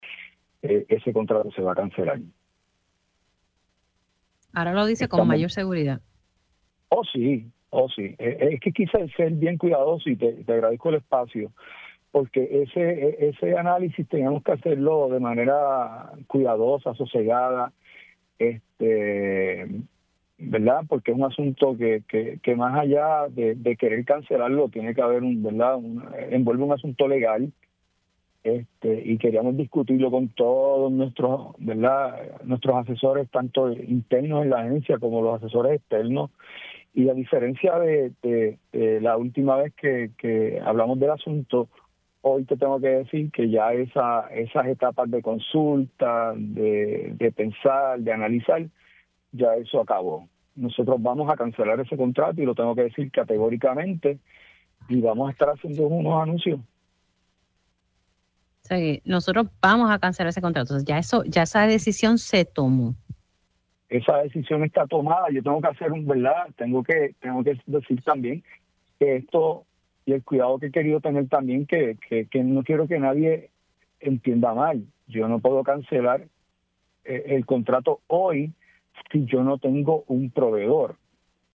El secretario de Corrección y Rehabilitación, Francisco Quiñones reveló en Pega’os en la Mañana que la agencia cancelará el contrato con Physician Correctional.